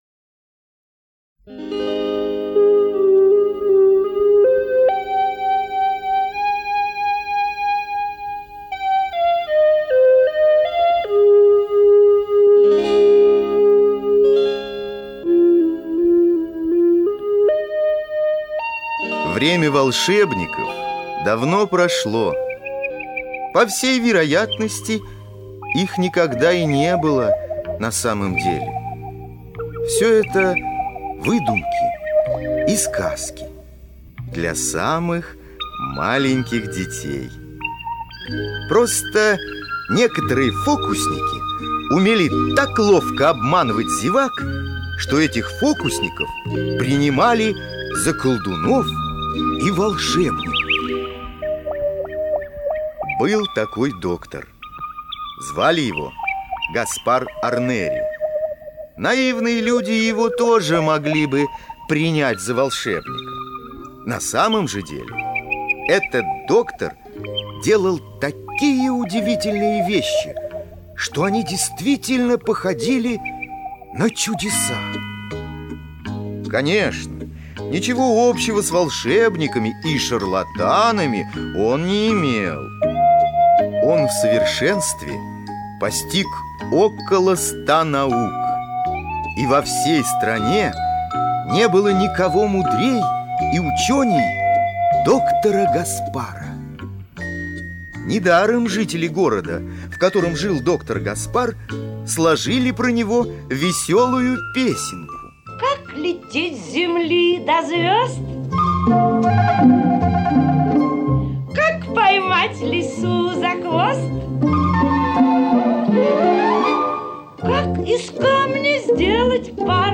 Три толстяка — слушать аудиосказку Юрий Олеша бесплатно онлайн